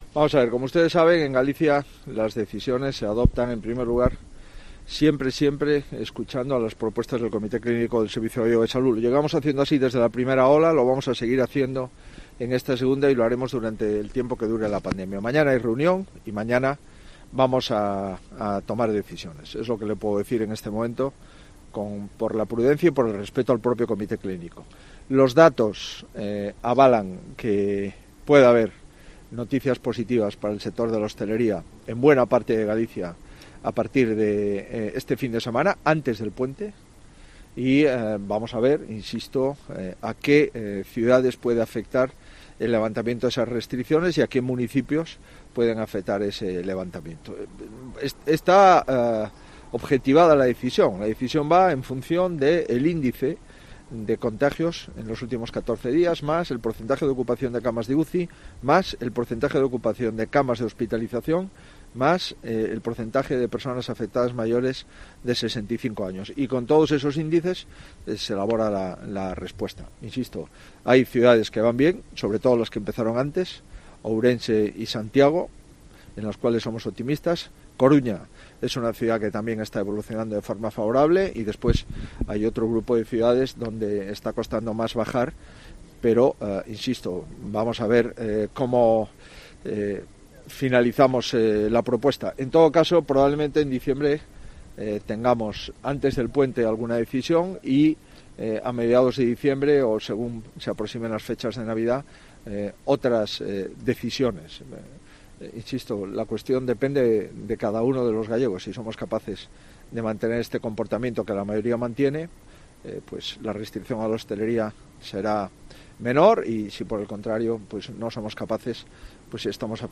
Declaraciones de Alberto Núñez Feijóo sobre buenas noticias para la hostelería